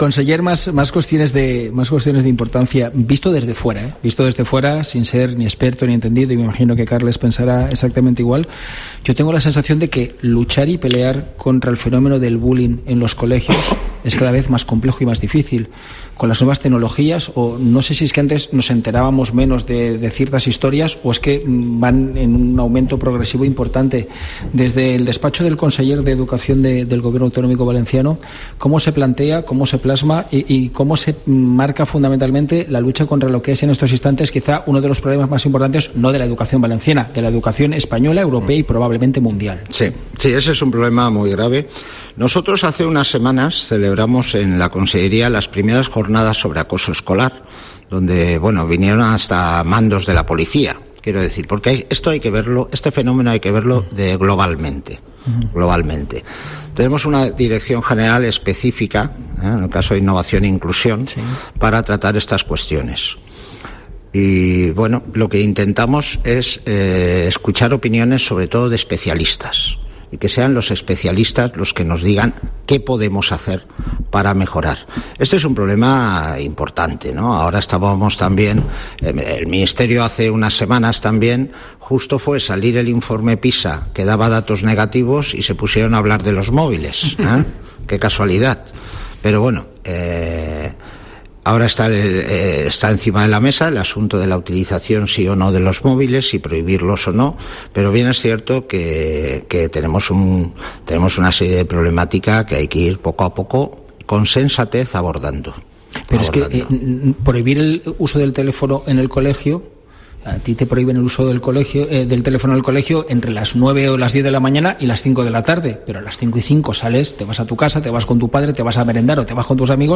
El conseller de Educación, José Antonio Rovira, visita el set de COPE Valencia en el día Mundial de la Radio
Rovira también destacaba en su intervención en el día mundial de la radio que celebró COPE Valencia en el Palau de la Generalitat que “desde que el informe PISA arrojó datos negativos sobre el acoso escolar, se ha puesto sobre la mesa el debate de si prohibir los móviles en las aulas o no”.